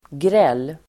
Uttal: [grel:]